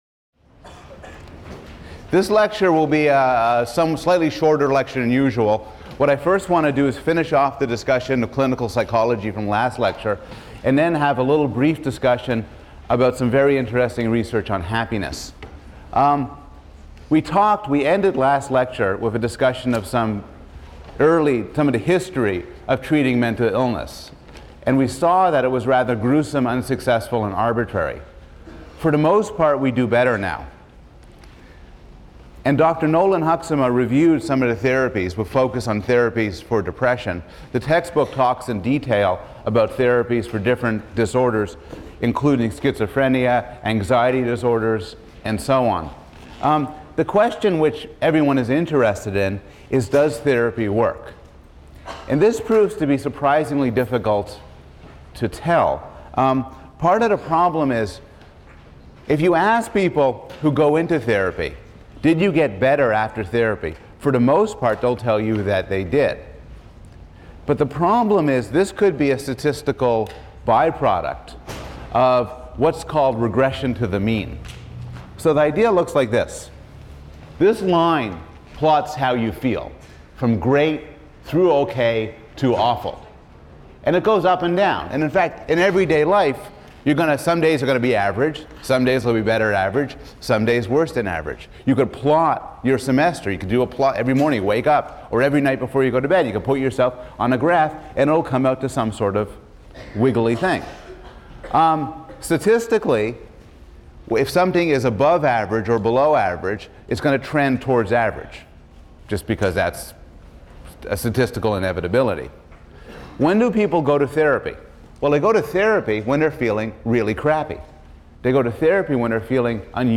PSYC 110 - Lecture 20 - The Good Life: Happiness | Open Yale Courses